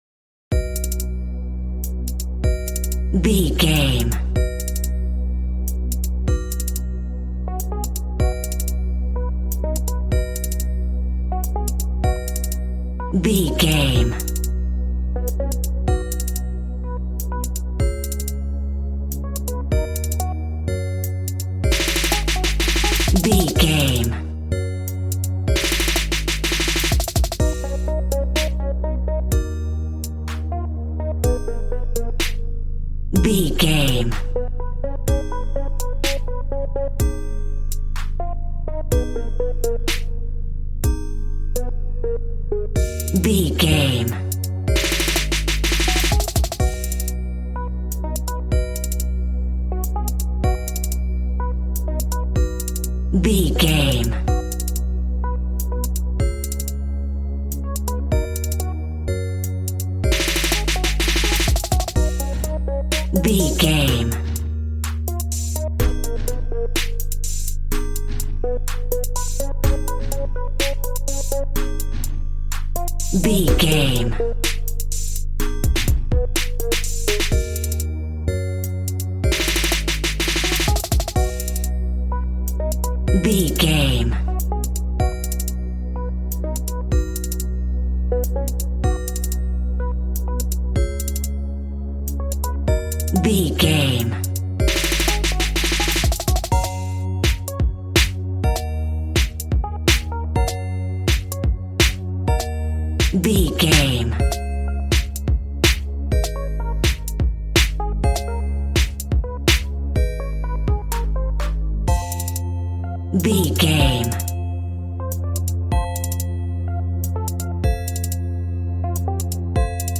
Ionian/Major
calm
smooth
synthesiser
piano